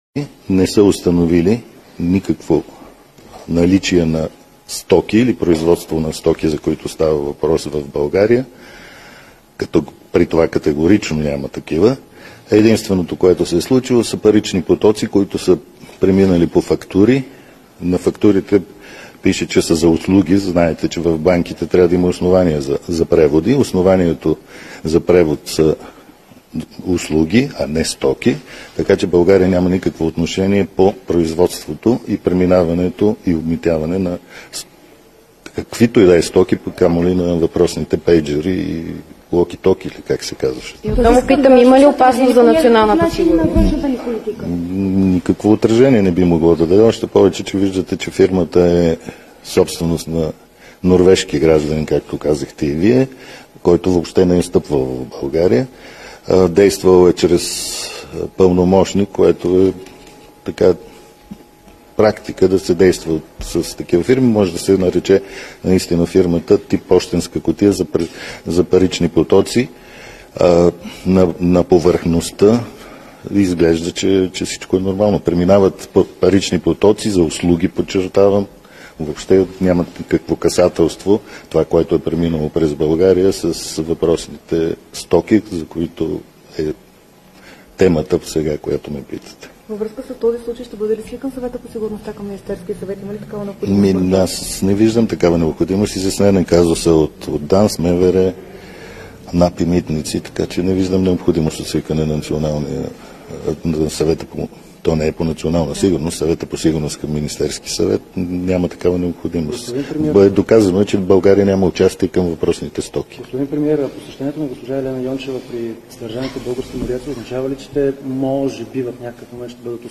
11.45 - Брифинг на Мартин Димитров от ПП-ДБ и евродепутата от ПП-ДБ Радан Кънев за обществени поръчки за сканиращи уреди по граничните пунктове. - директно от мястото на събитието (Народното събрание)